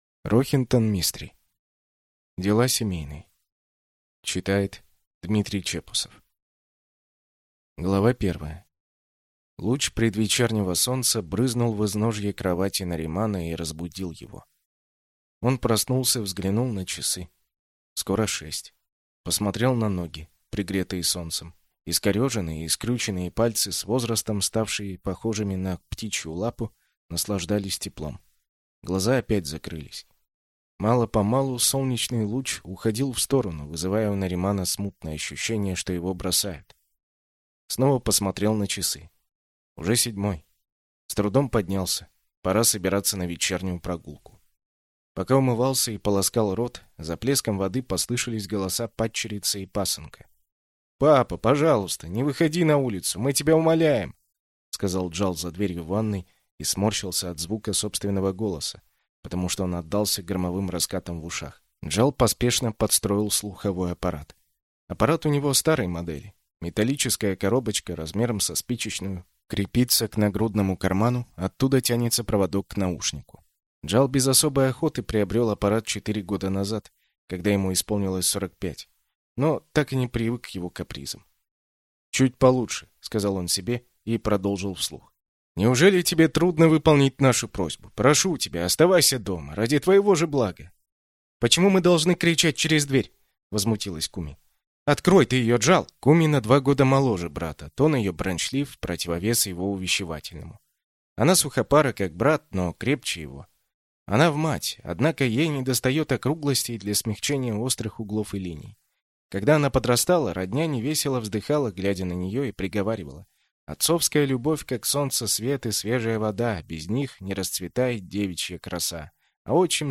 Аудиокнига Дела семейные | Библиотека аудиокниг